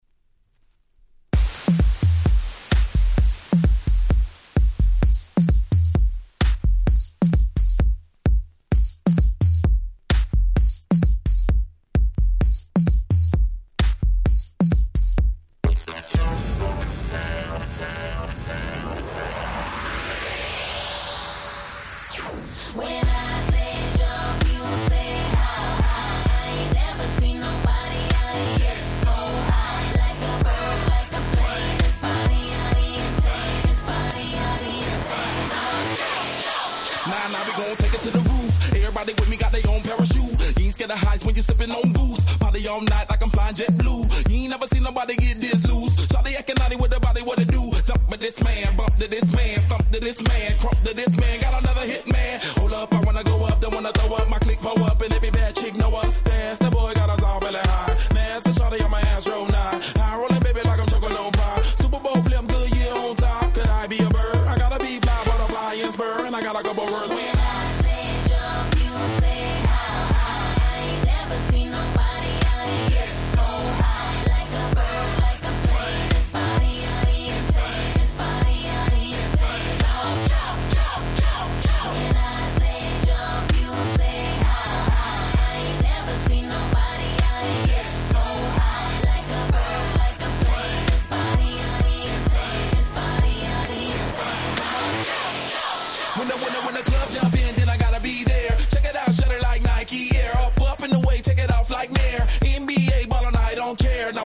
09' Smash Hit Hip Hop/Pop !!